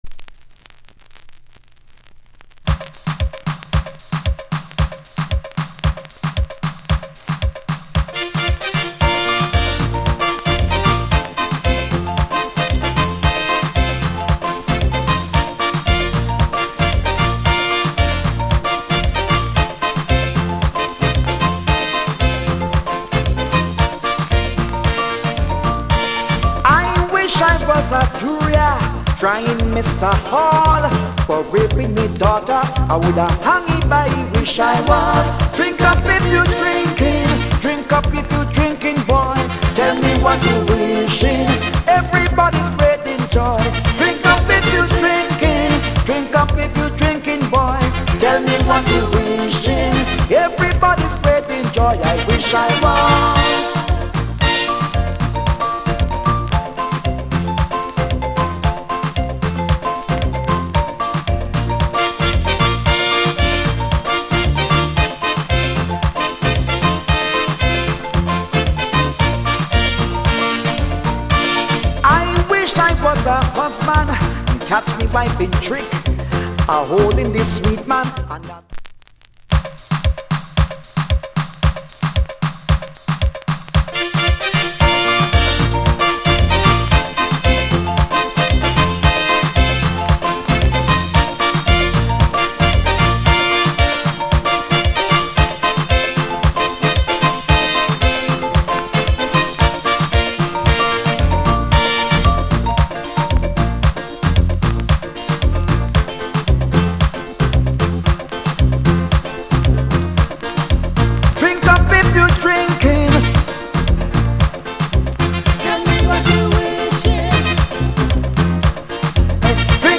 * '87 Soca Vocal.